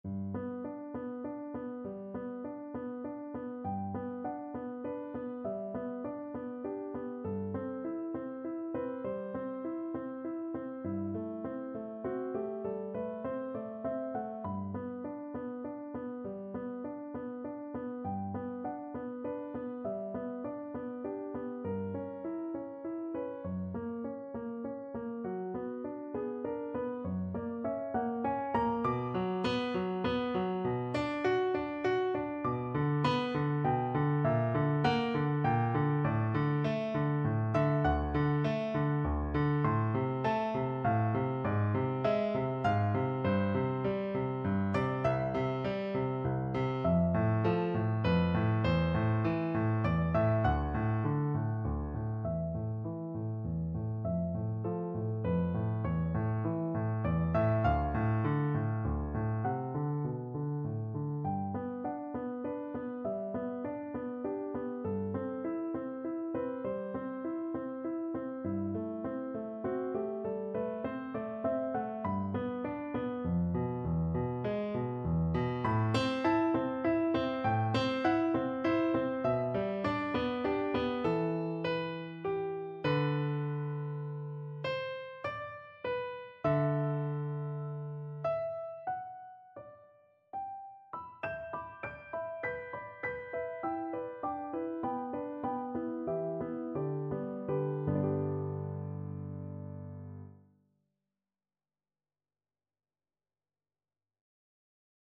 Piano version
Classical Piano